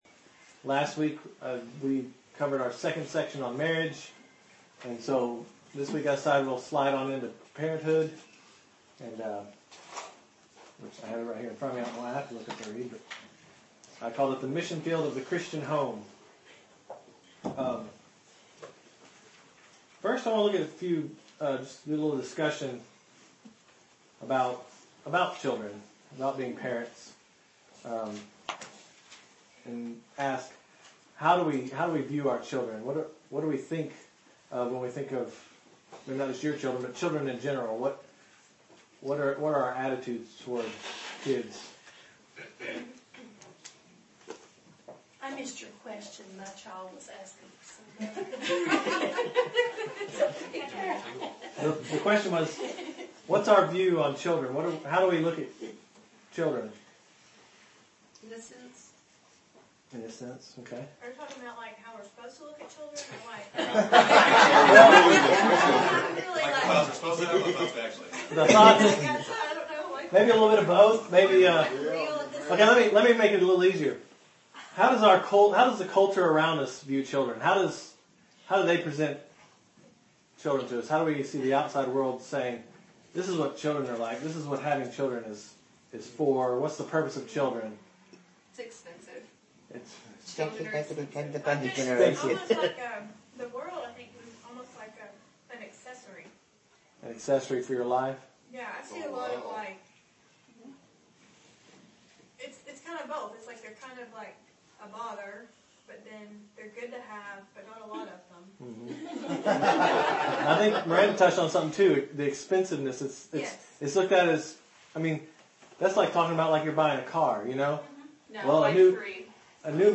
Recorded live in our midweek study series.